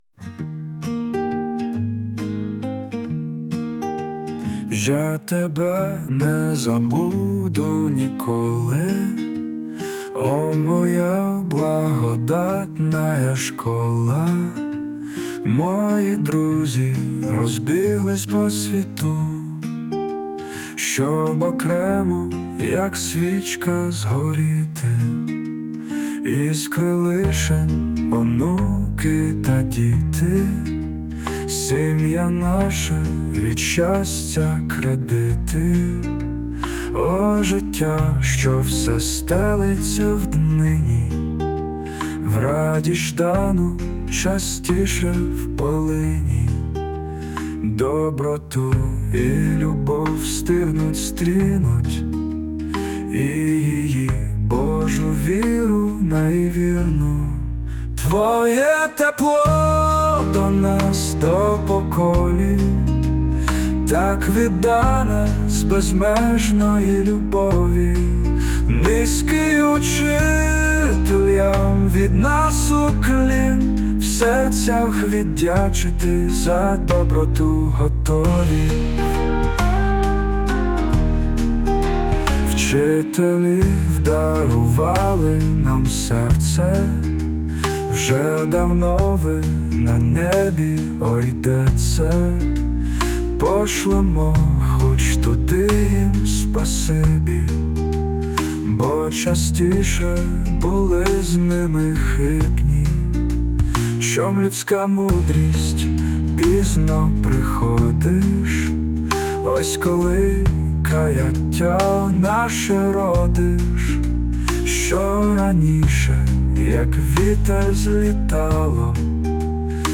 Мелодія на слова пісні: